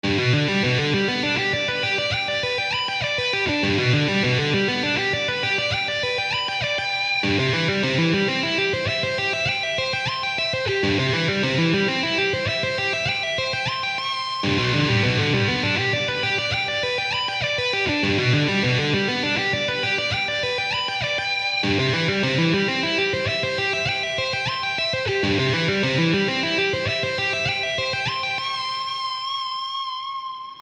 Click the following links to view some examples of rock guitar technical studies for intermediate and advanced students.
G (I) and C (IV) Arpeggio Study
G-I-and-C-IV-Arpeggio-Study.mp3